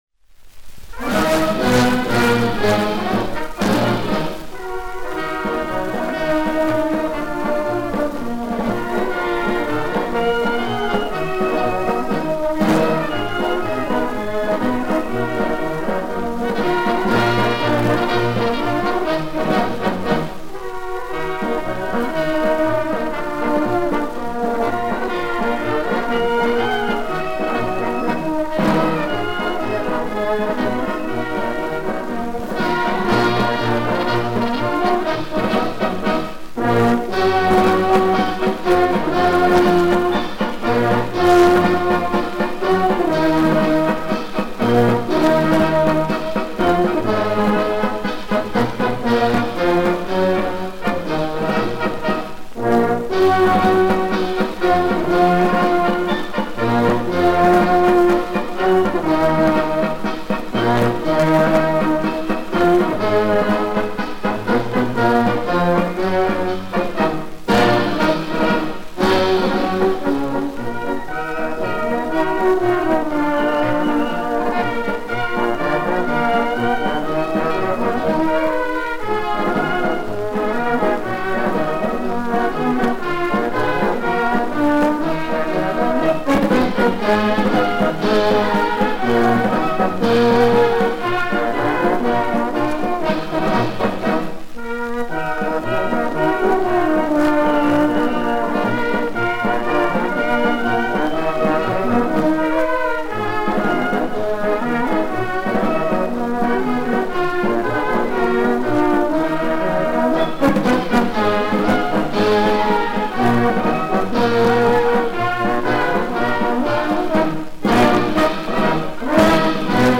Марш был создан для военного оркестра, без слов.
По стилистическим признакам можно с большой долей вероятности утверждать, что это запись крупного советского оркестра 30-х—50-х годов.